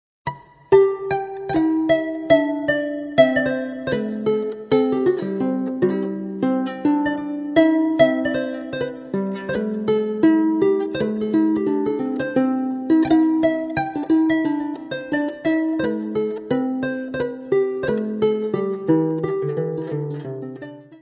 : stereo; 12 cm
Área:  Tradições Nacionais